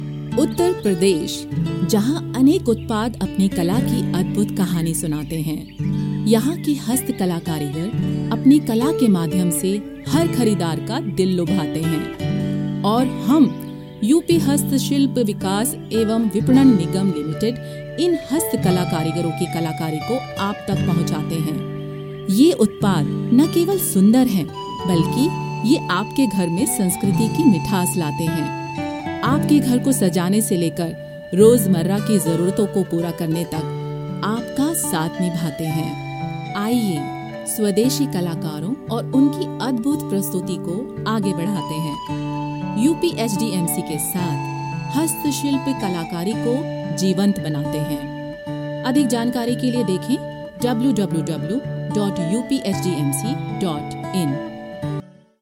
baritone voice